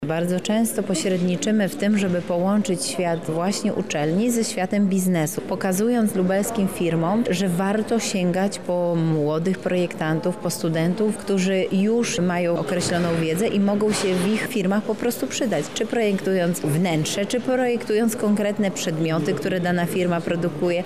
Więcej o tym, jak wygląda współpraca ze strony miasta mówi Zastępca Prezydenta Miasta Lublin ds. Kultury, Sportu i Partycypacji, Beata Stepaniuk-Kuśmierzak: